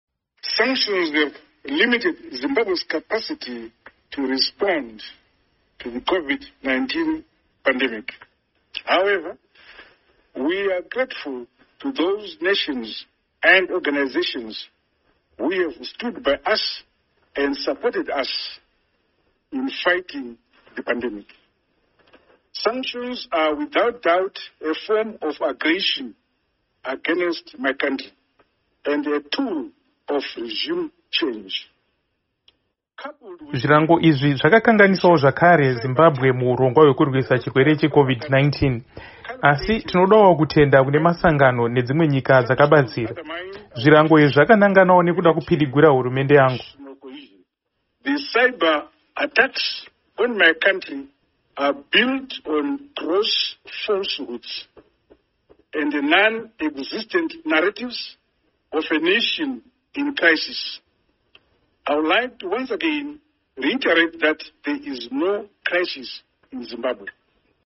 VaMnangagwa Vachitaura Pamusoro peZvirango